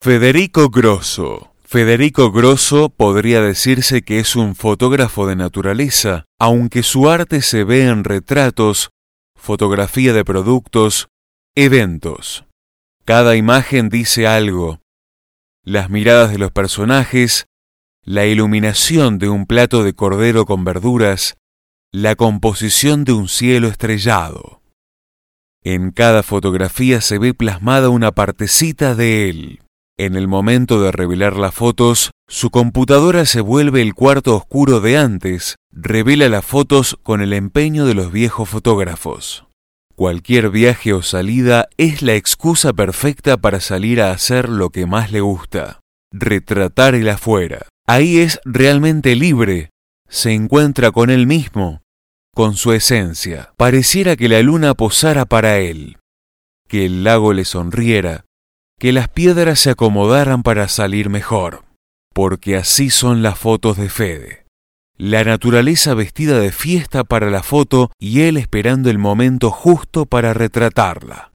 Un plus de la muestra son las audio guias, los visitantes podrán acceder por medio de su teléfono a un relato que ilustra conceptualmente el trabajo de cada artista, con solo sacar una foto a un codigo QR junto a las obras, (utilizando una rápida aplicación) se podrá conocer más sobre el universo simbólico de sus creadores.